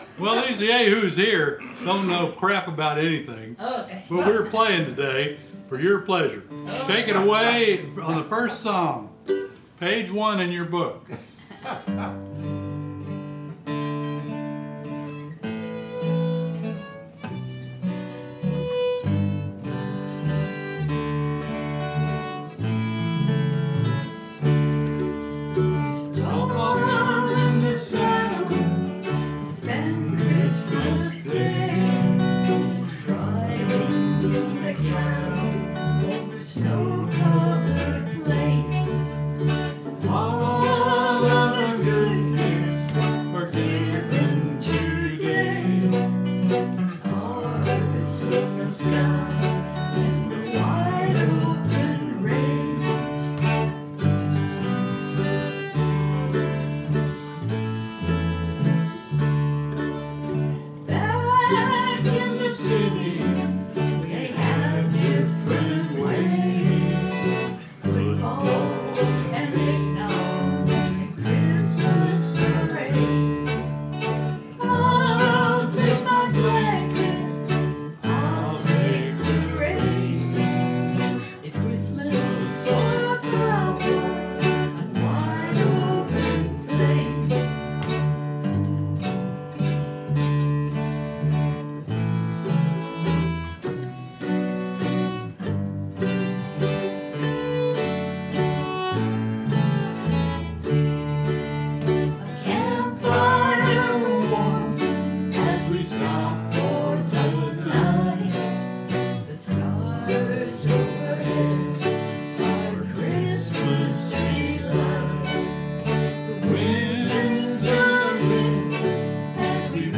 Practice track